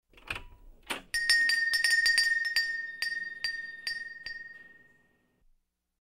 Звуки дверных колокольчиков
Открыли дверь — раздался звон колокольчиков